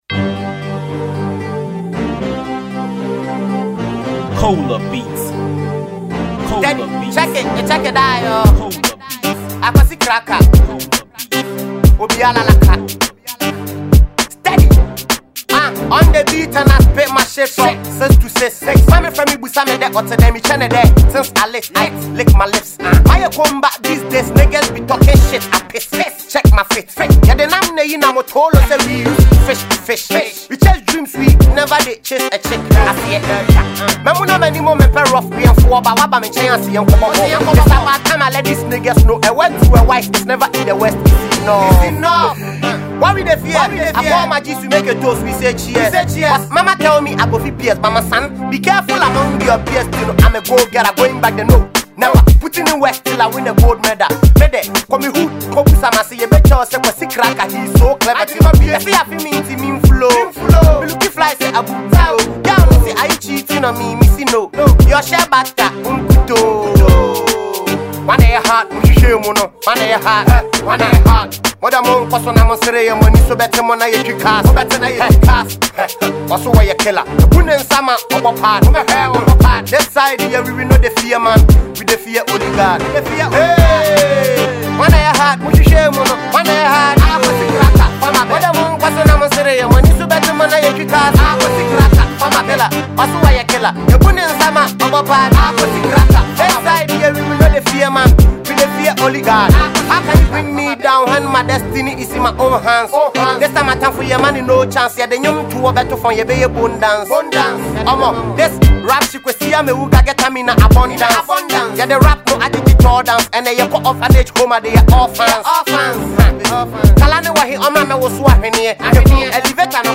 Ghana Music
Ghanaian rapper